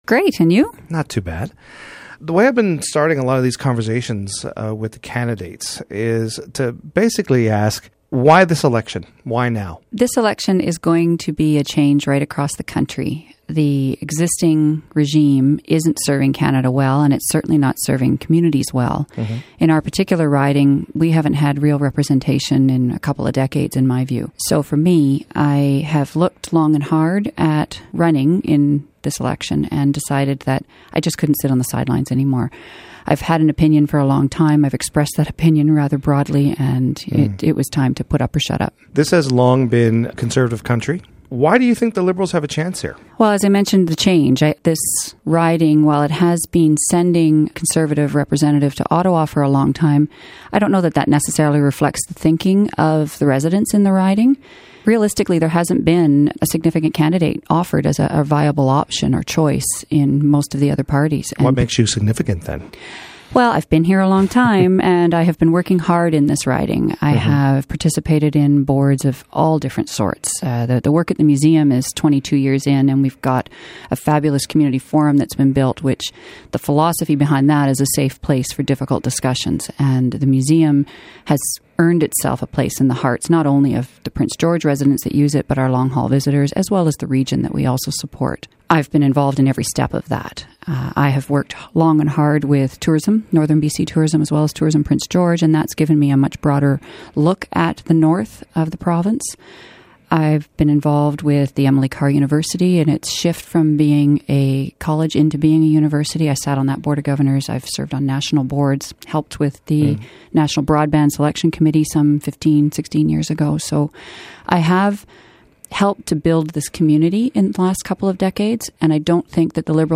In the lead-up to next month's federal election, we have invited every candidate in our northern B-C ridings to join us on-air.